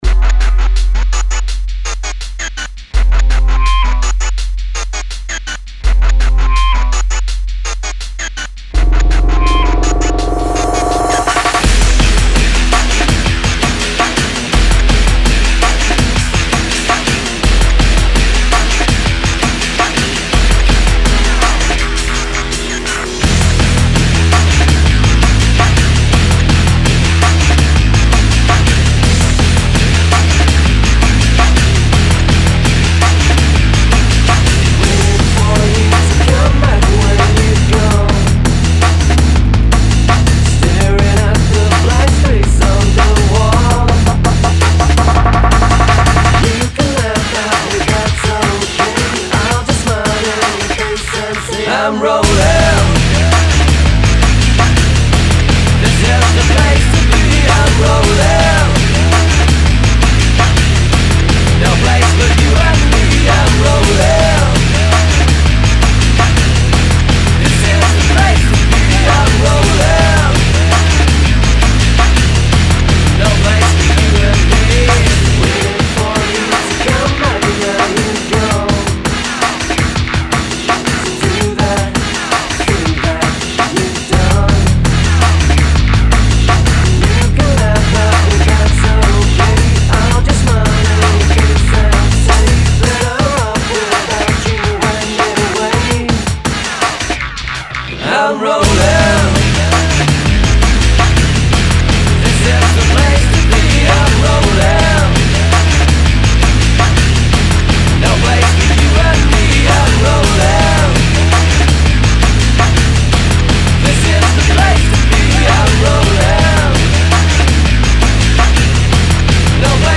phat drum'n'bass groove and monumental bassline
Remains a strong band favourite and a corking live tune.